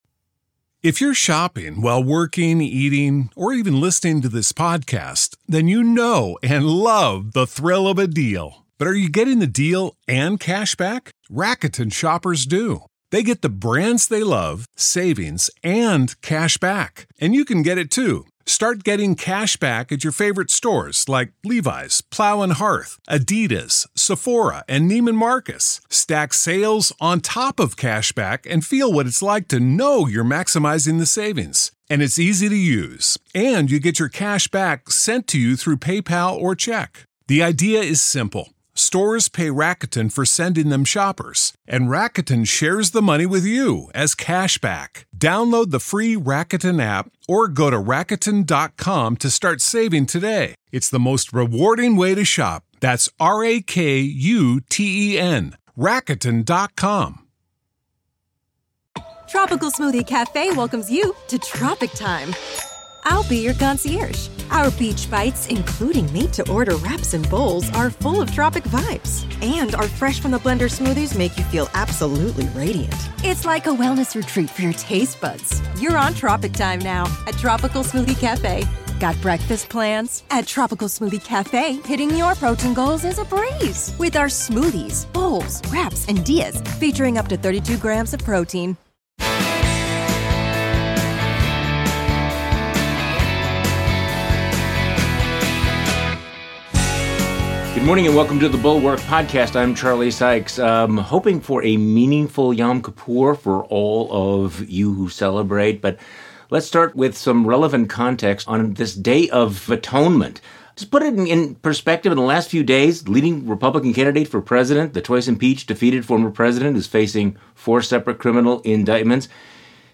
Trump Ever After: Live from TribFest